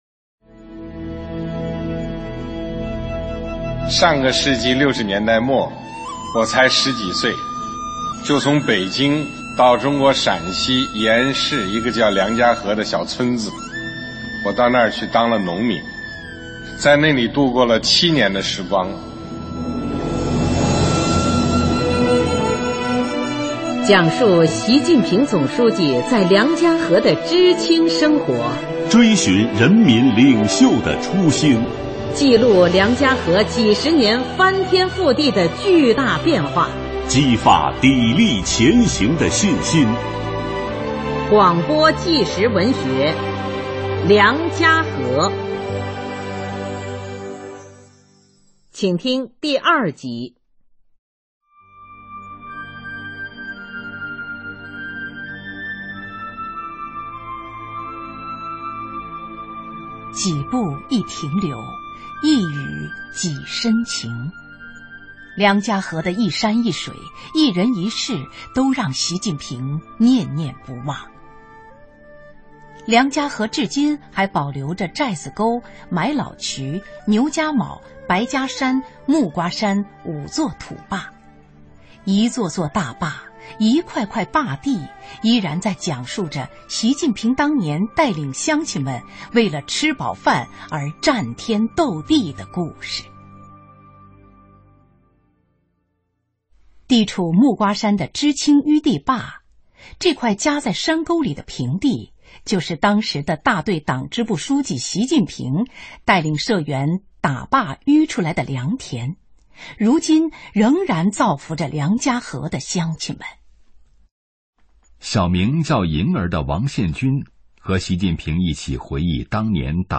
广播纪实文学《梁家河》第二集：美味的酸菜学习讲堂